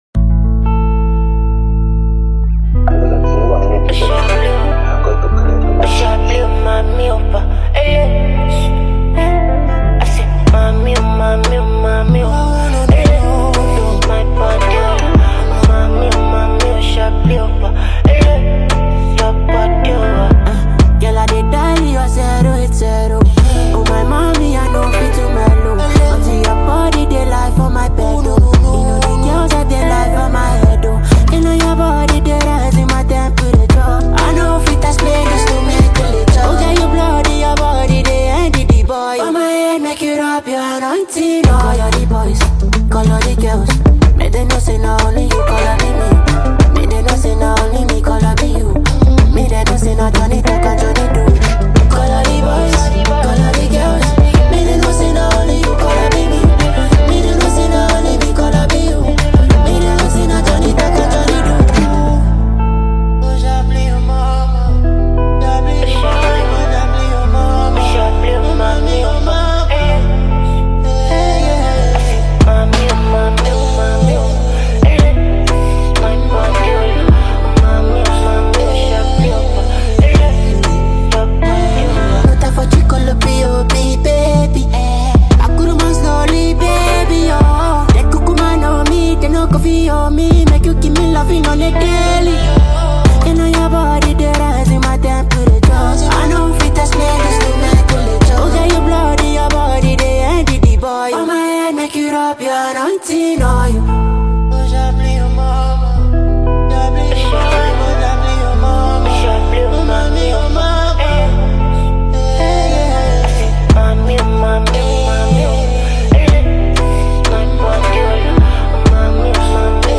a multi-gifted Nigerian vocalist, and lyricist.